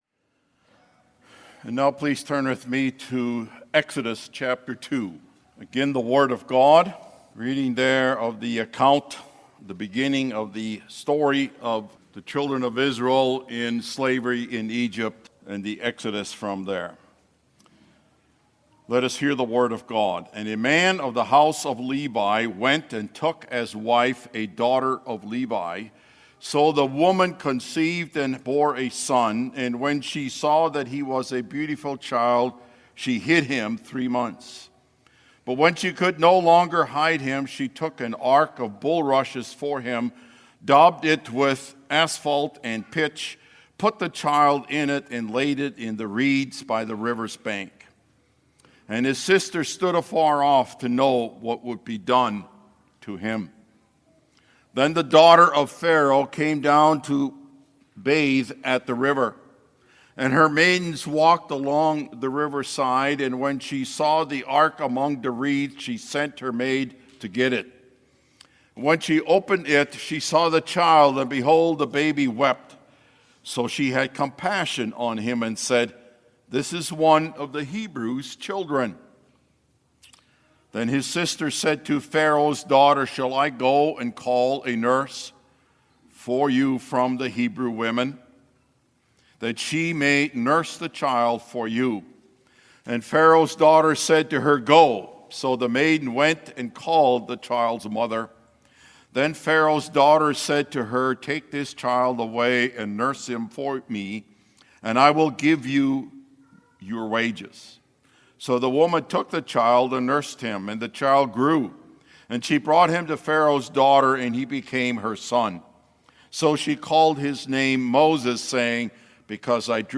The Reality of Salvation | SermonAudio Broadcaster is Live View the Live Stream Share this sermon Disabled by adblocker Copy URL Copied!